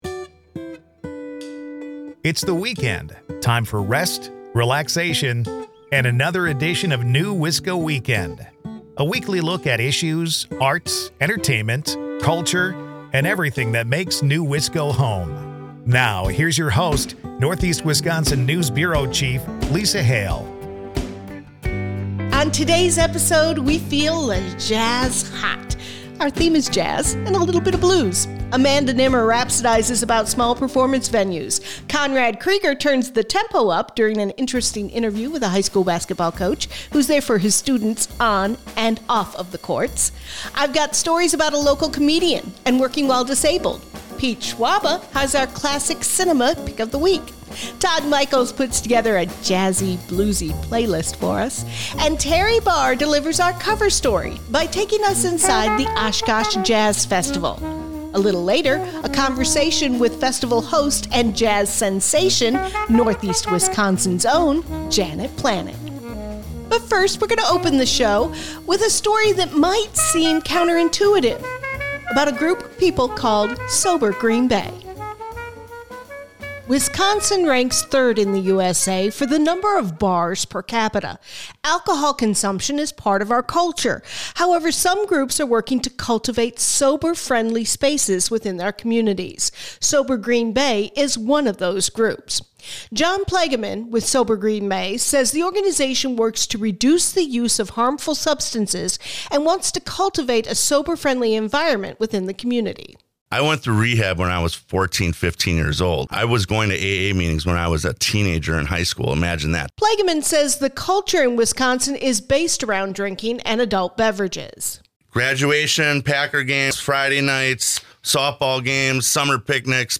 NEWisco Weekend is a part of the Civic Media radio network and airs Saturdays at 8am and Sundays at 11am on 97.9 WGBW and 98.3 WISS.
This week On New Wisco Weekend…Jazz.. and a bit of the Blues.